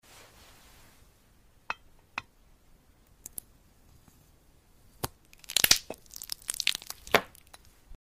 Glass Passion Fruit Cutting ASMR